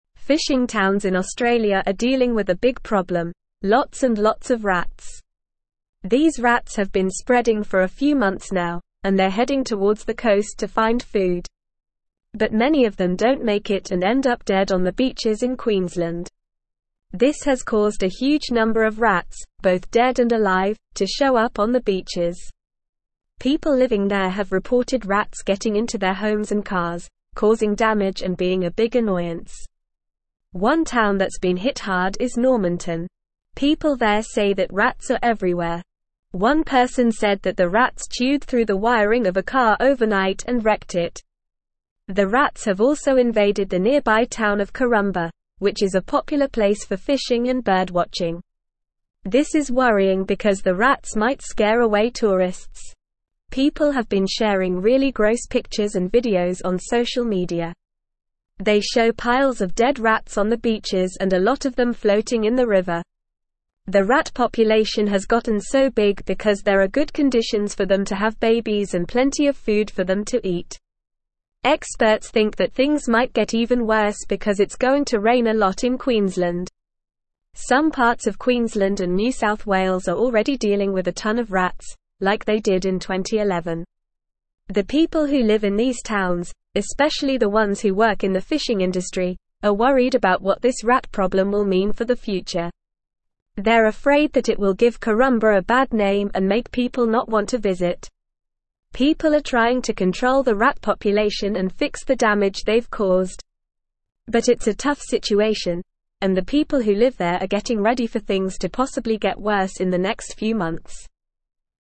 Normal
English-Newsroom-Upper-Intermediate-NORMAL-Reading-Rat-and-Mouse-Plague-Hits-Queenslands-Fishing-Towns.mp3